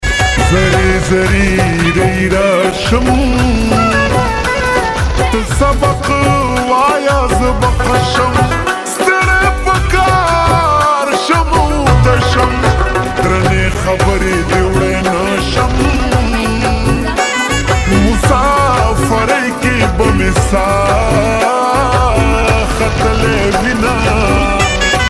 Pashto sweet song with gaming sound effects free download